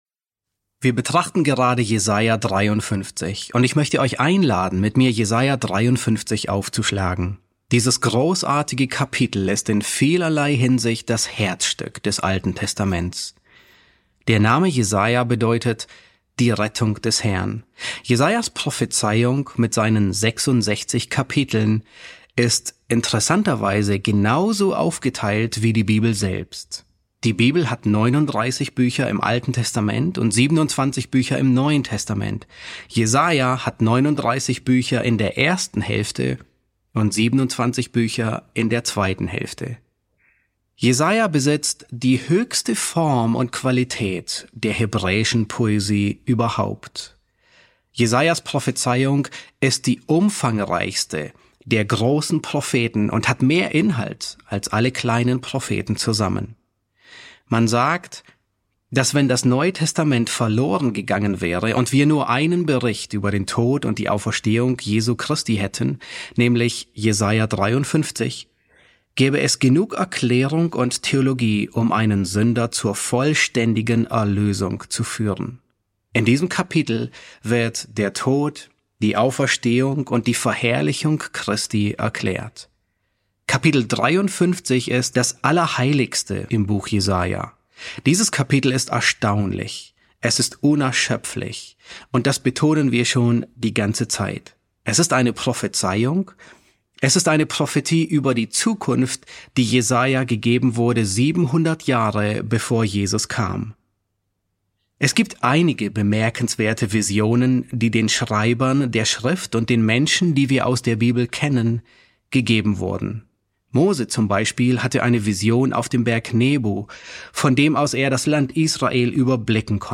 S4 F5 | Der stellvertretende Knecht, Teil 1 ~ John MacArthur Predigten auf Deutsch Podcast